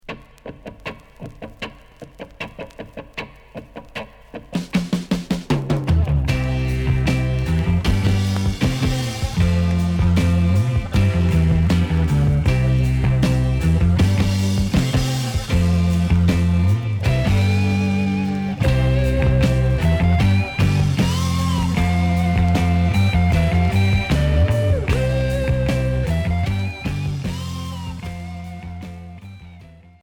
Pop progressive Premier 45t retour à l'accueil